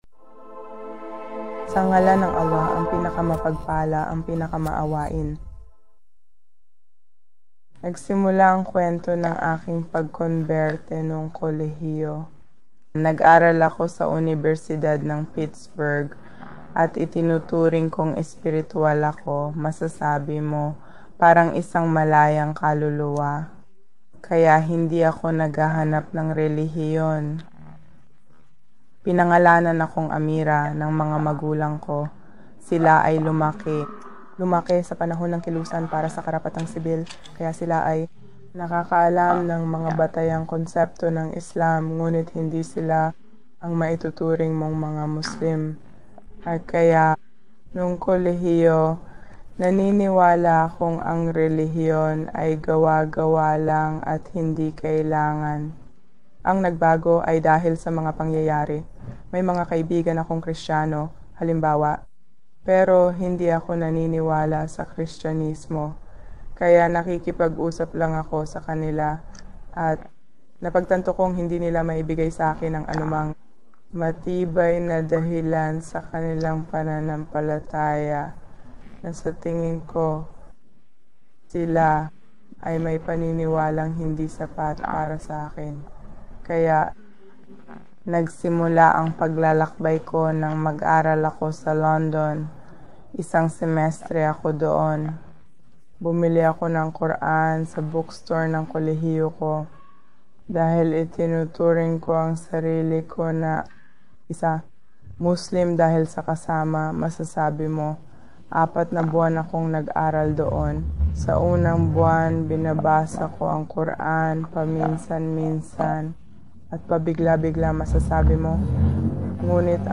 Videos Mga Kwento ng Bagong Muslim Kababaihan